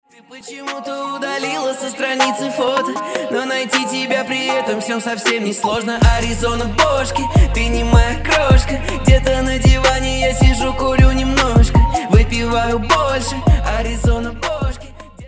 • Качество: 321, Stereo
лирика
русский рэп
качающие